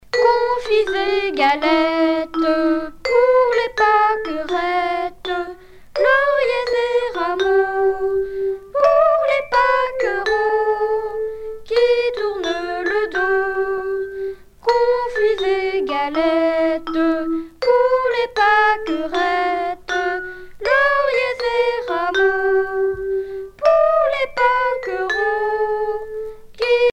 Genre brève
Catégorie Pièce musicale éditée